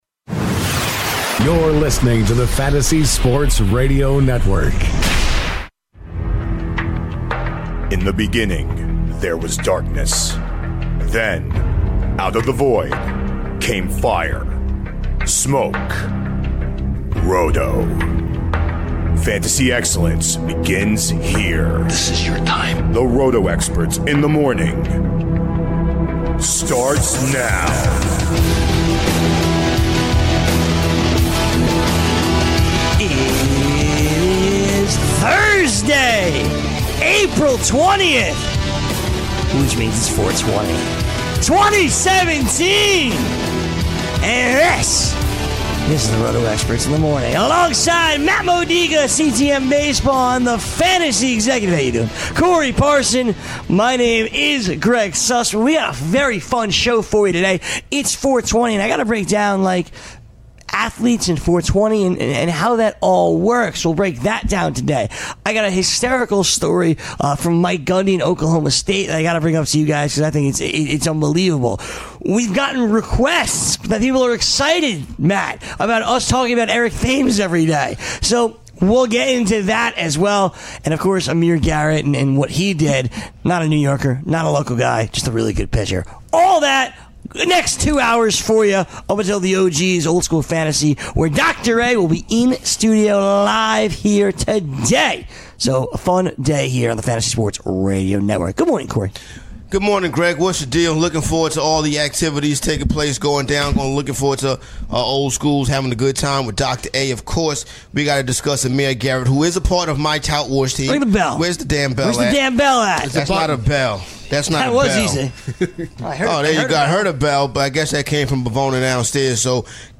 Later, the Experts take callers and give trade and free agency advice.